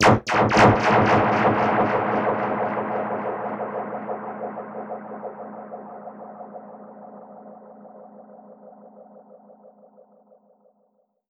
Index of /musicradar/dub-percussion-samples/85bpm
DPFX_PercHit_C_85-04.wav